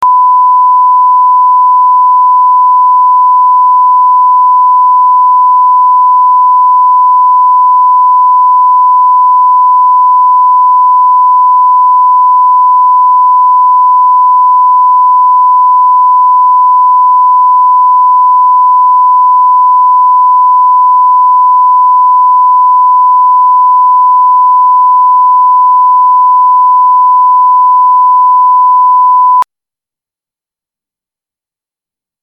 Auch diese Dateien wurden alle als WAV-Dateien aufgezeichnet, nur die Datenmengen und der Zweck haben mich dazu bewogen alle WAV-Dateien in MP3-Dateien zu wandeln.
Sinustöne
Sinus-1000-Hz.mp3